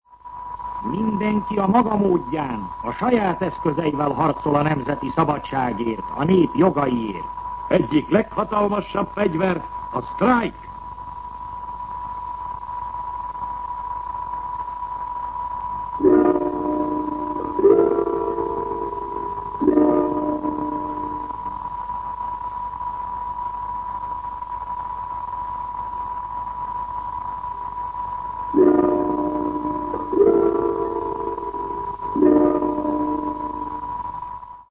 Szünetjel